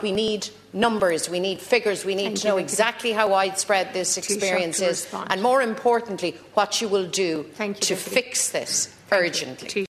Party leader Mary Lou McDonald told the Dáil chamber, lives are on the line, particularly in the West and North West…….